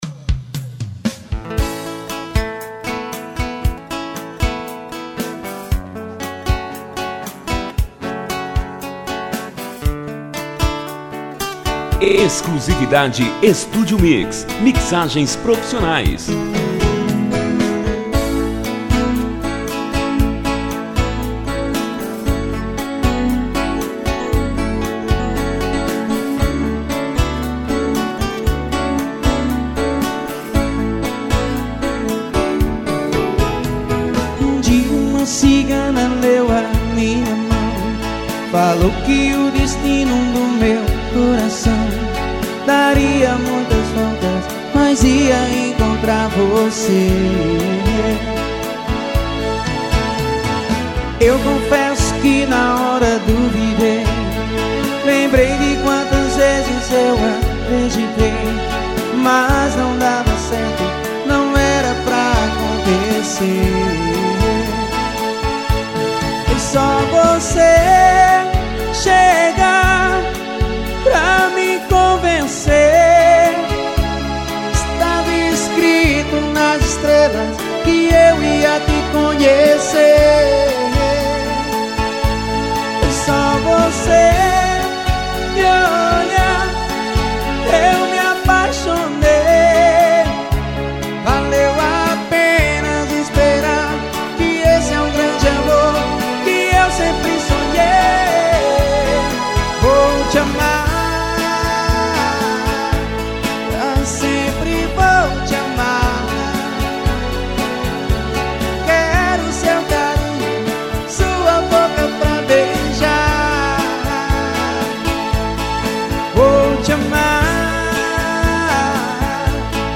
sertanejo universitario.